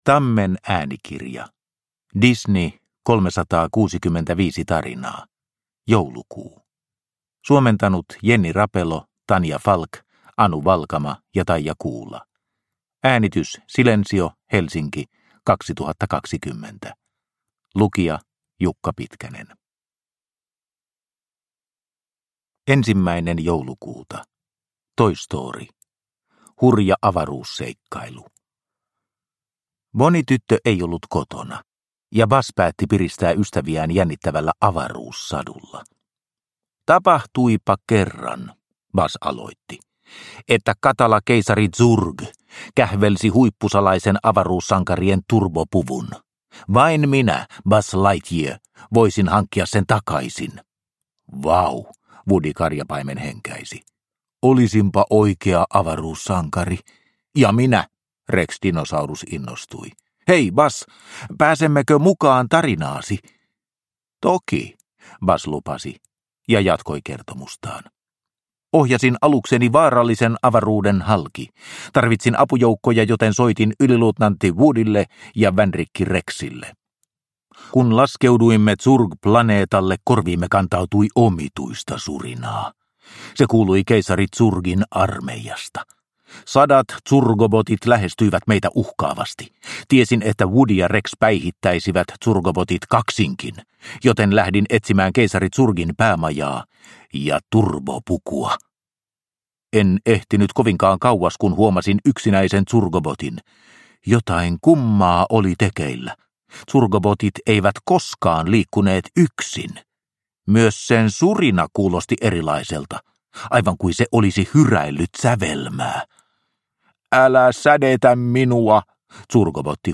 Disney 365 tarinaa, Joulukuu – Ljudbok – Laddas ner